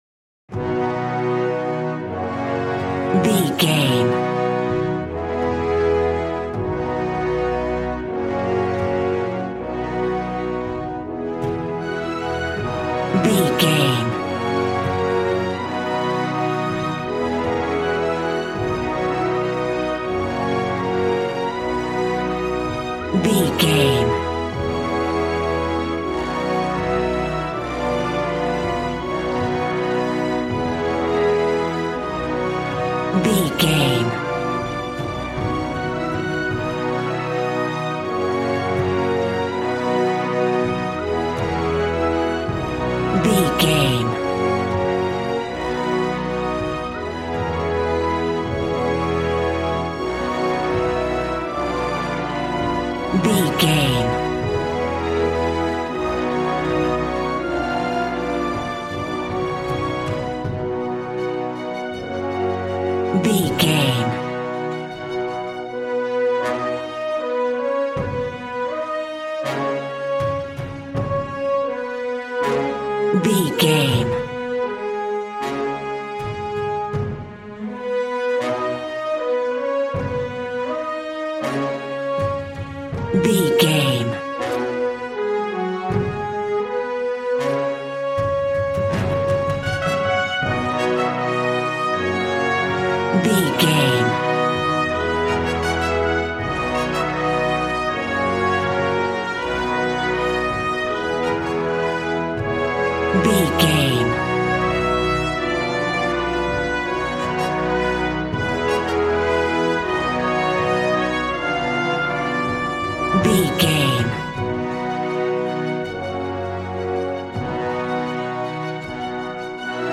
Ionian/Major
brass
strings
violin
regal